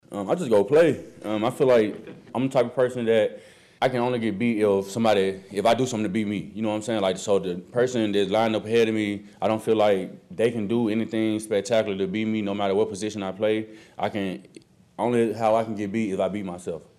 Elgton Jenkins was the second player meeting with reporters after practice.